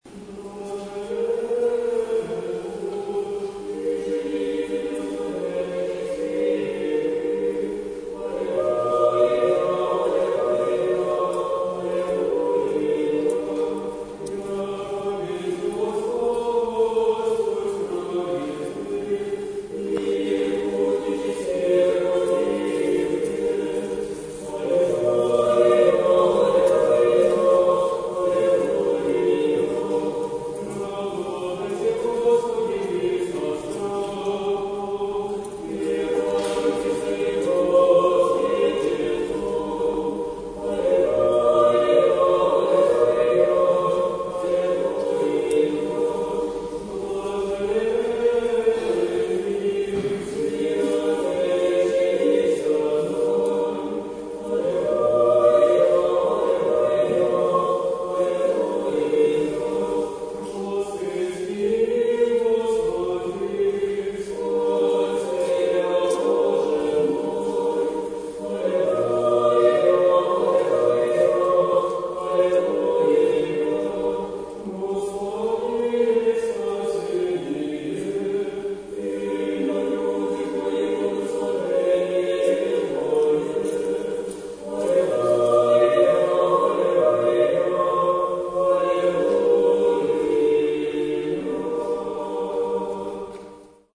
Запись хора Самсониевского собора г.Санкт-Петербурга